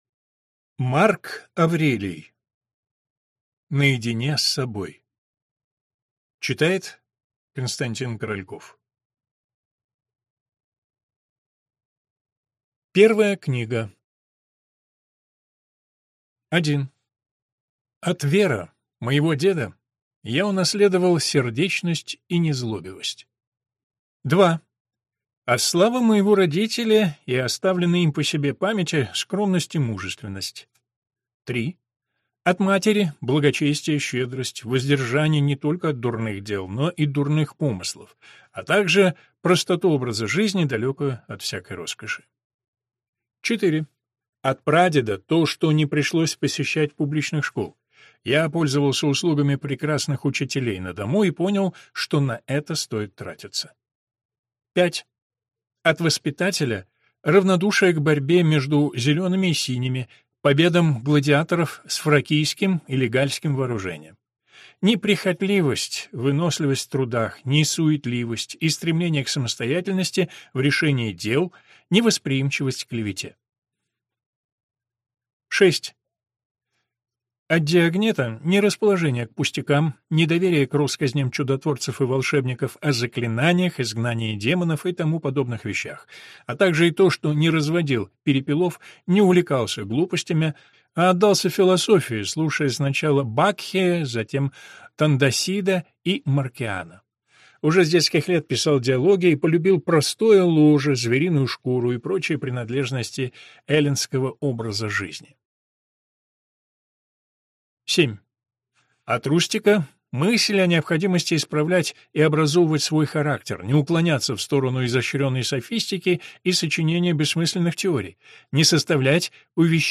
Аудиокнига Наедине с собой | Библиотека аудиокниг